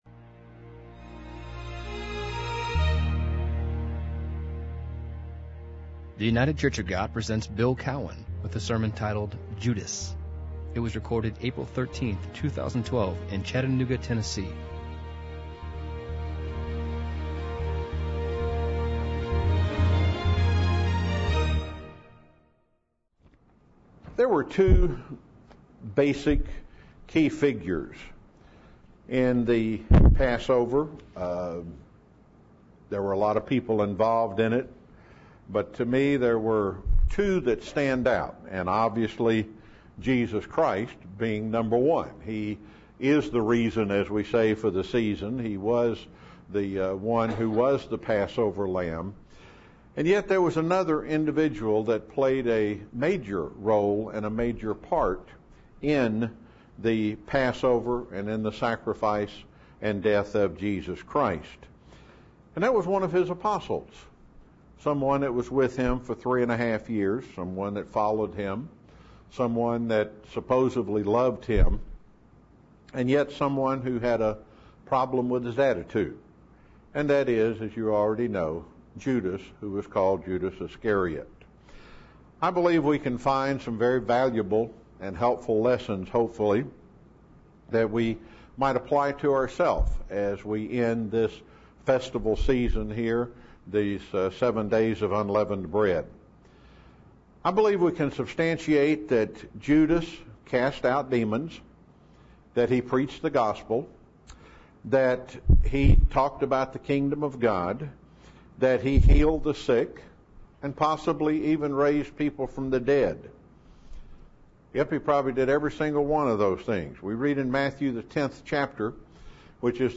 [The sermon was given on the Last Day of Unleavened Bread in 2012]